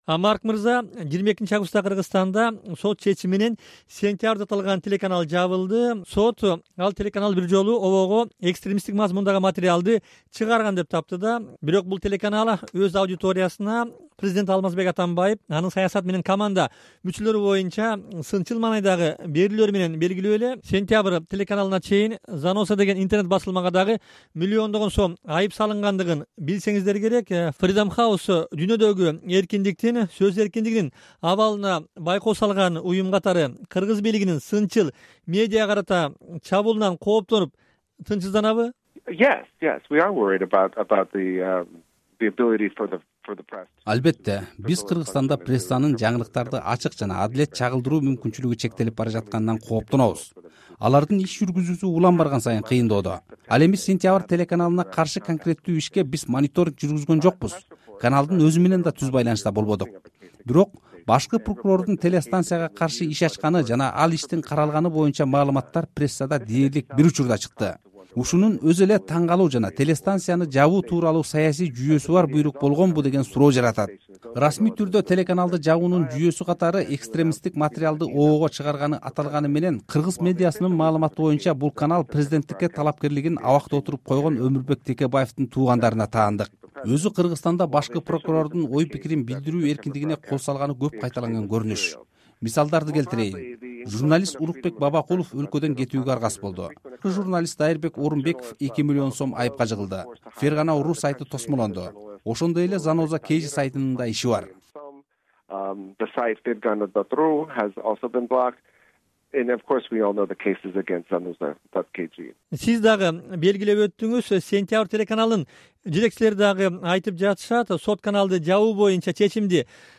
"Азаттыктын" суроолоруна жооп берди.